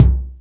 Lotsa Kicks(07).wav